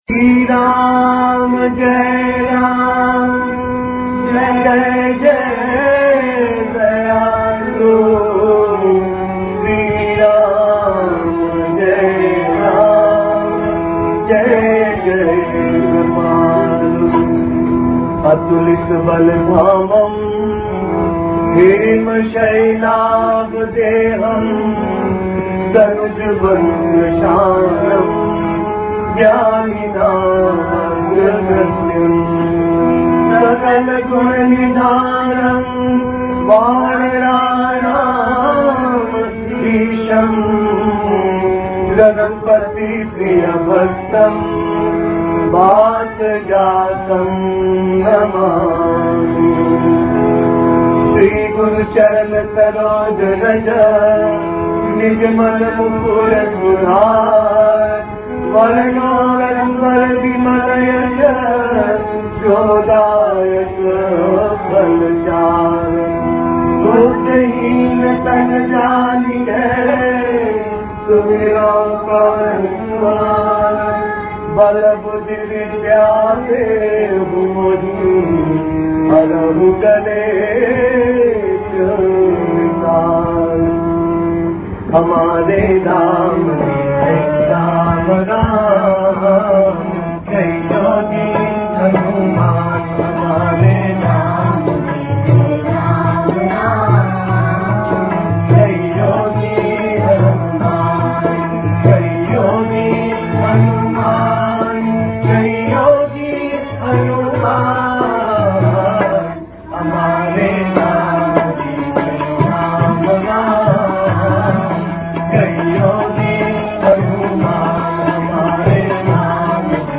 भजन कीर्तन आरती, हिंदी भजन,राम परिवार भजन, श्री राम शरणं,